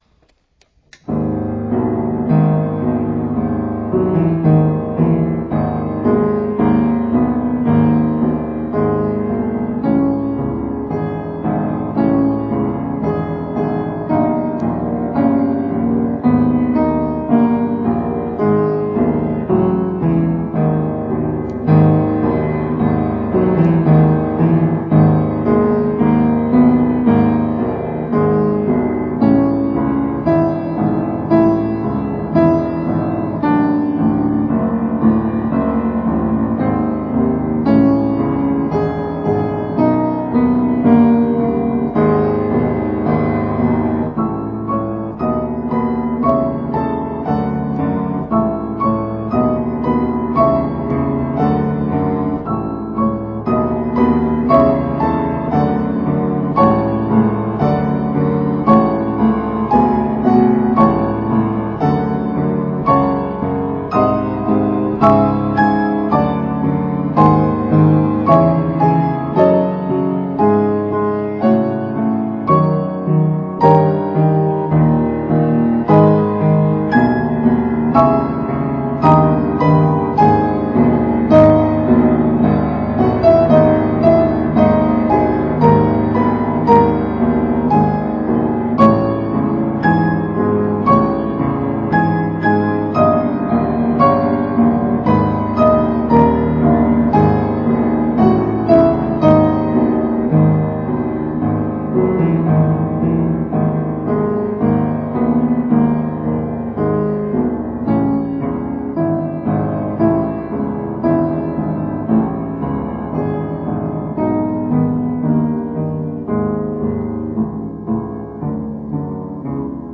ピアノコンサート２
ピアノ曲としての独自の解釈があっていいんだろうなぁって思いつつ弾いてます。
自宅のGPによる演奏録音 　　 デジピによる演奏録音